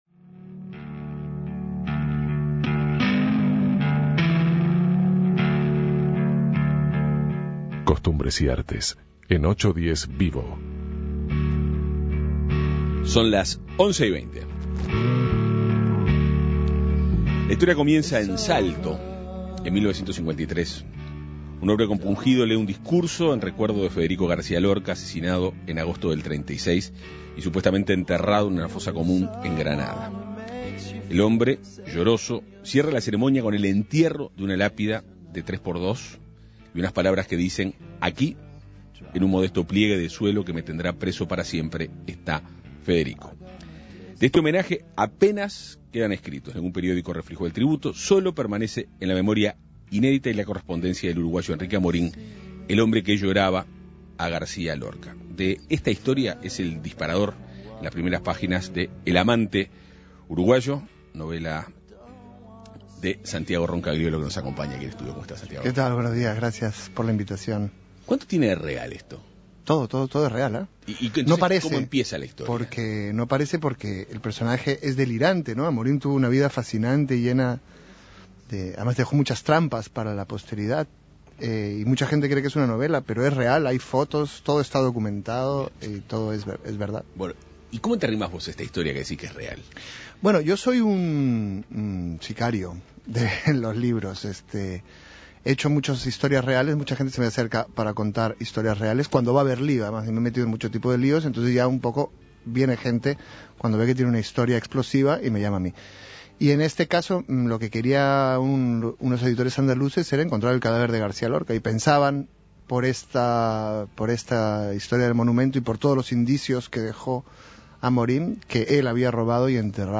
El escritor peruano Santiago Roncagliolo está en Montevideo para presentar su nuevo libro: "El amante uruguayo", en el que aborda la vida del narrador y poeta Enrique Amorim. Entre otras cosas, Roncagliolo busca la verdad tras el rumor que Amorim habría conseguido adueñarse del cadáver del poeta Federico García Lorca. Para hablar de "El amante uruguayo", 810VIVO Avances, tendencia y actualidad recibió a autor en estudios.